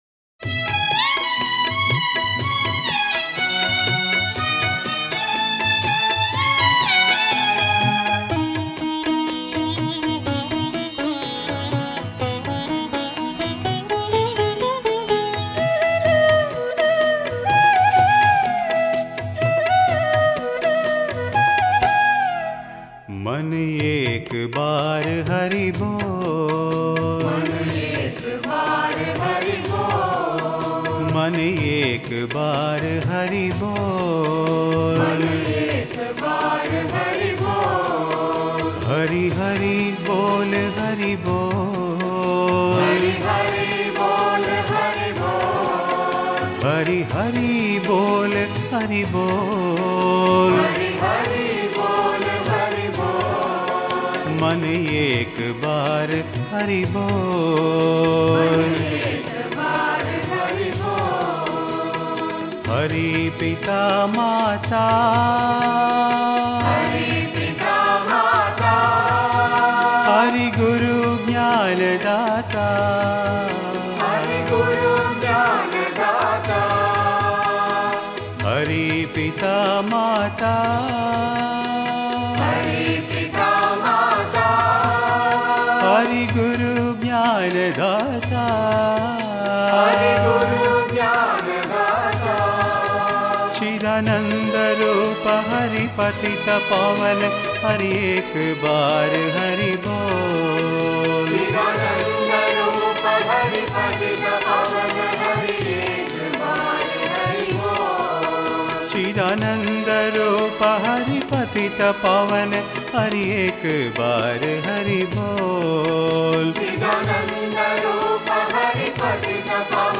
Author adminPosted on Categories Guru Bhajans